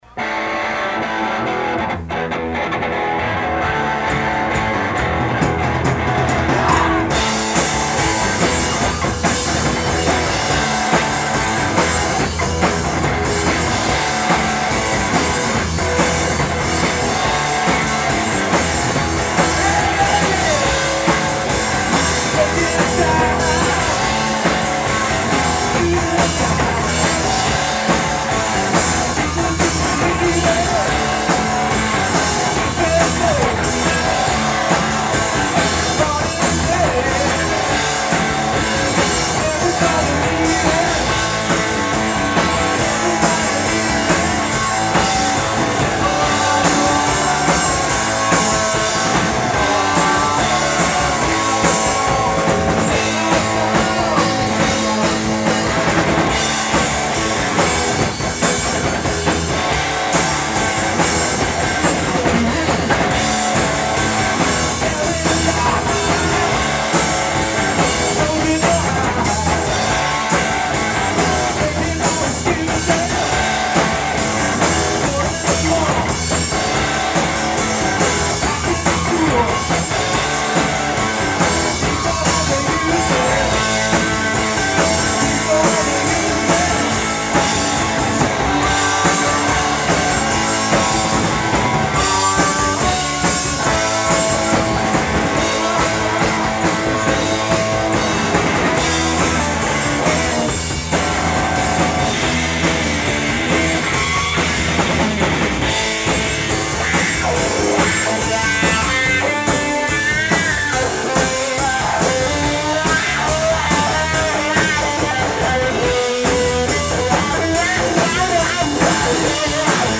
Khyber Pass, Philadelphia 11-25-94